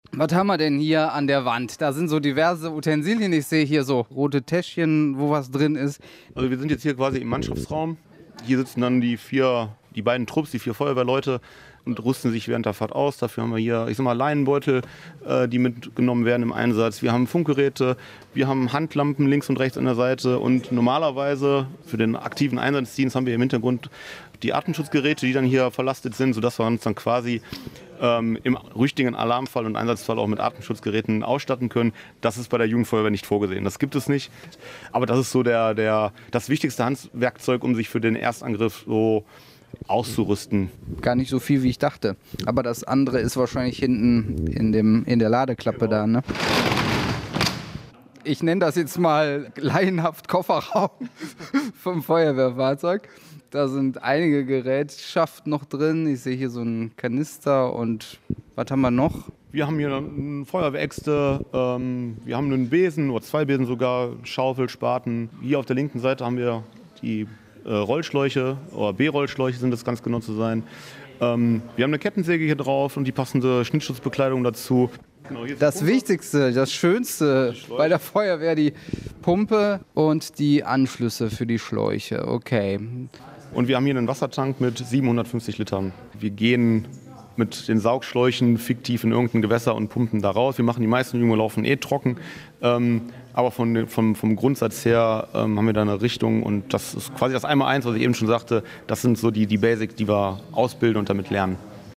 Reportage Kompaktes Übungsauto für die Jugendfeuerwehr